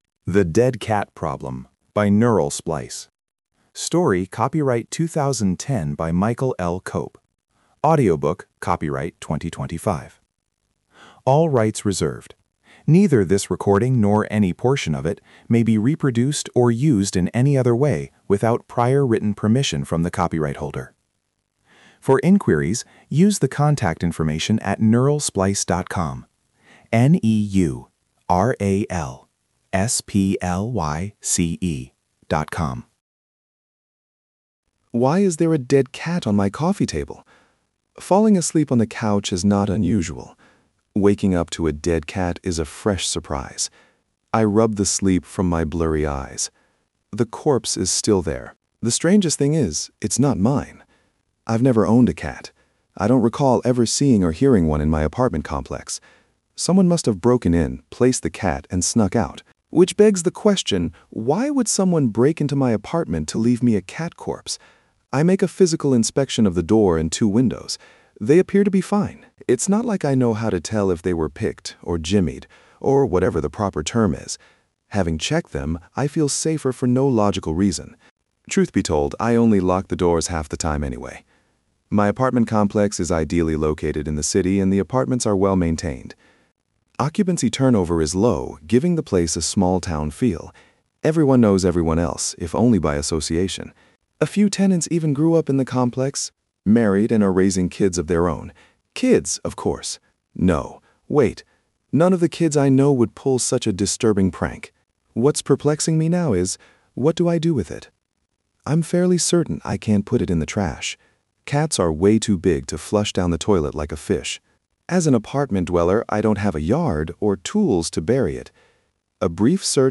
Return to Bookshelf The Dead Cat Problem Donate up to $3 Download ebook Download audiobook